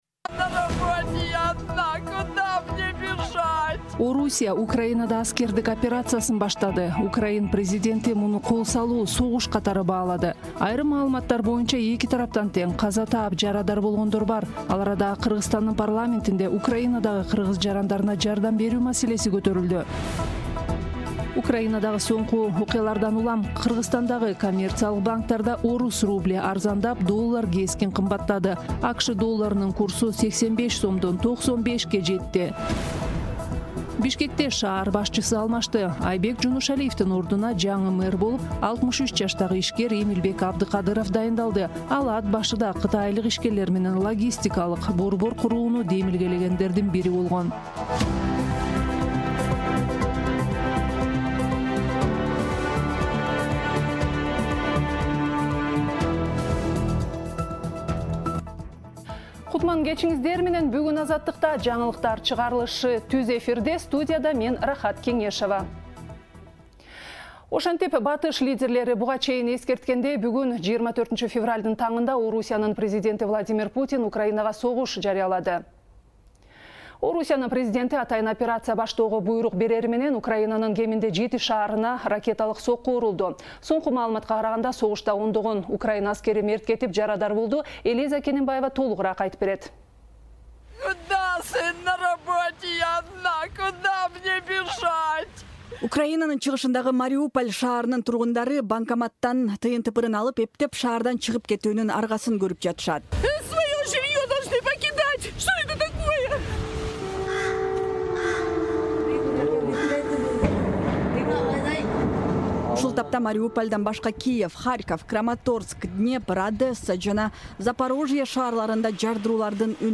Жаңылыктар | 24.02.2022 | Орус-Украин согушу.